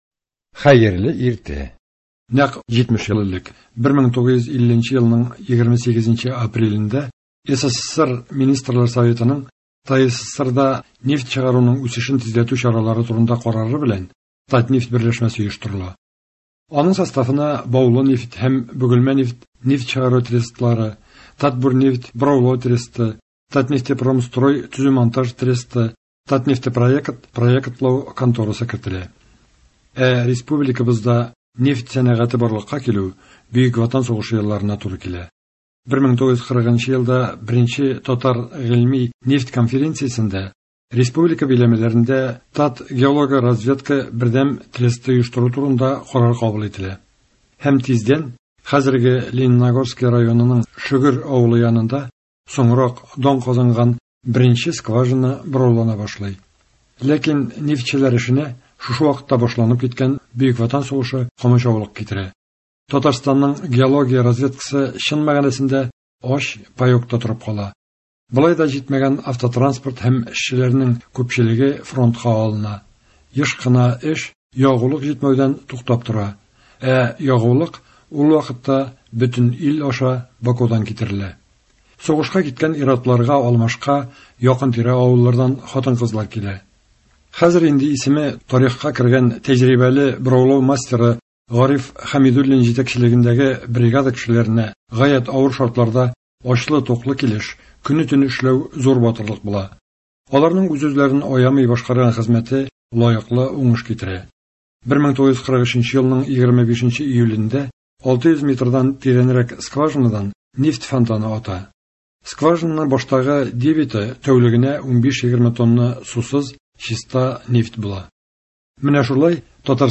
репортажларда кара алтын табучыларның хезмәт һәм ял шартлары, мәдәният һәм социаль өлкәдәге яңалыклар чагылыш таба.